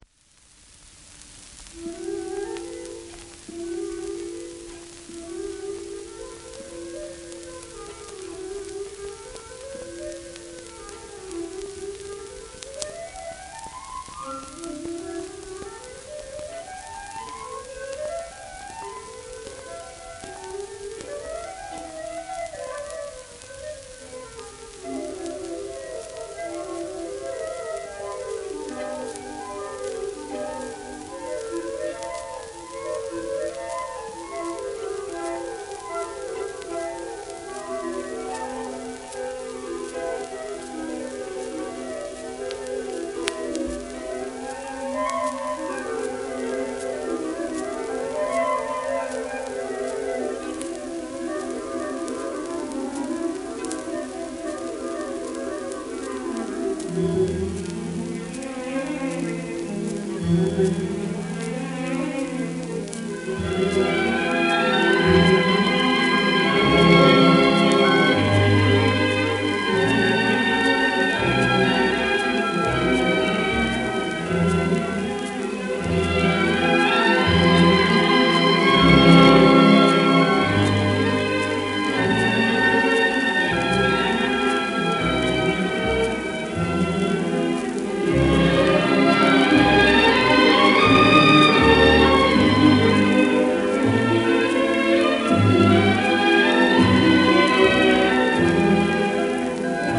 エーリヒ・クライバー(cond:1890-1956)
w/ベルリン国立歌劇場管
1929年録音
シェルマン アートワークスのSPレコード